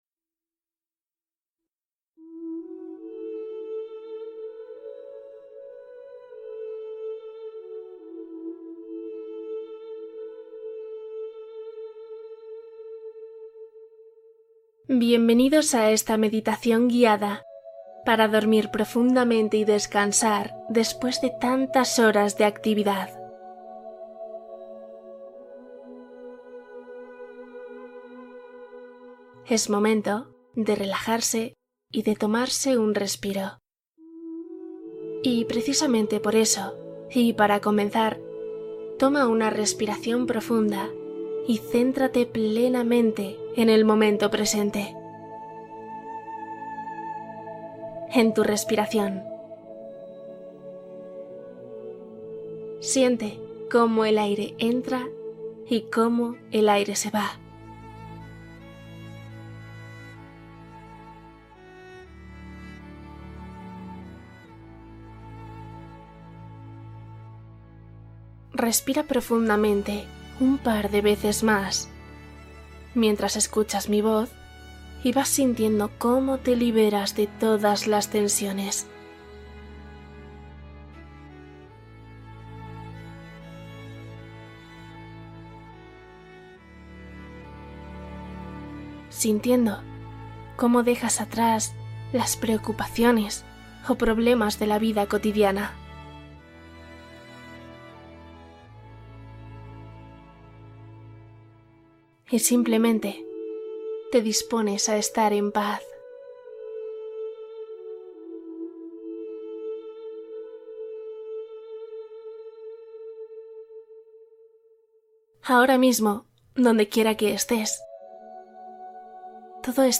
Meditación para dormir rápido, sanar y relajarte profundamente